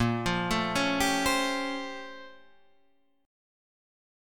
A# Minor Major 13th